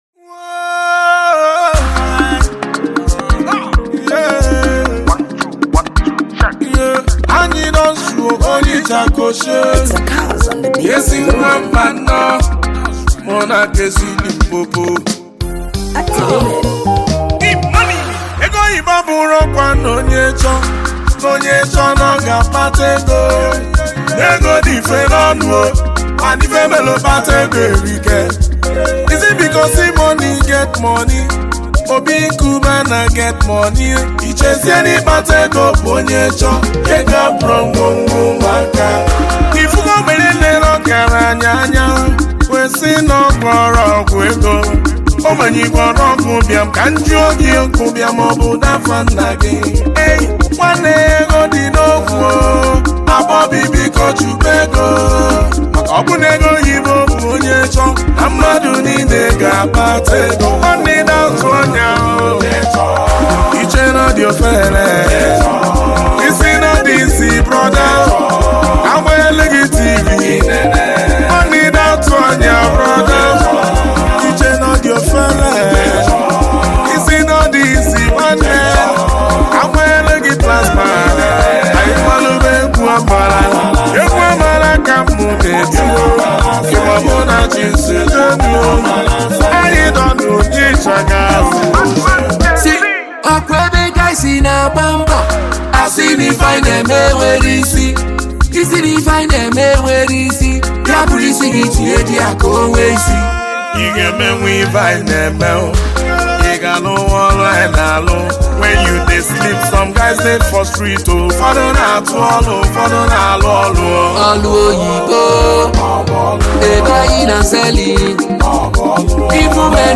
Gospel
afrobeat & highlife